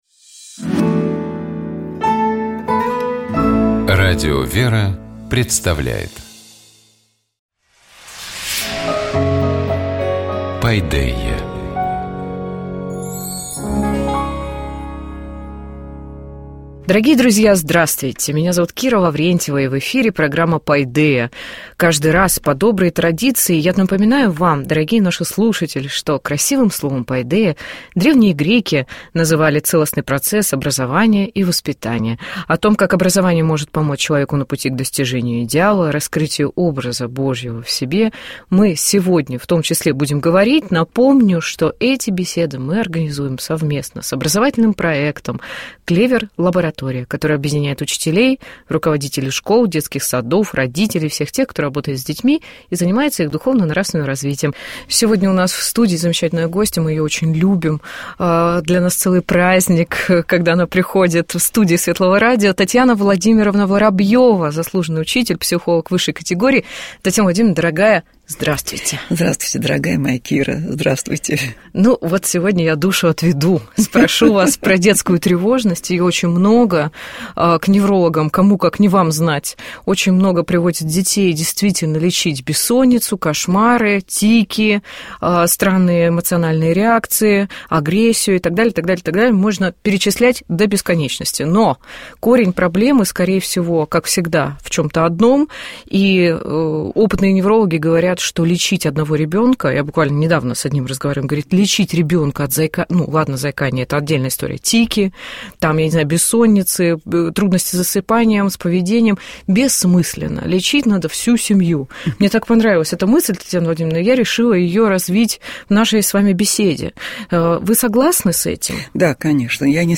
О Творении Ефрема Сирина «На Рождество Христово» — епископ Тольяттинский и Жигулёвский Нестор.